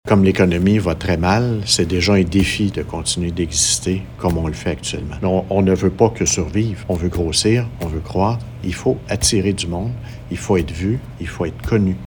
Dans son discours du budget tenu récemment, le maire de Maniwaki, Louis-André Hubert, a mis l’accent sur l’importance pour ce centre régional de préserver son statut et de croître à nouveau :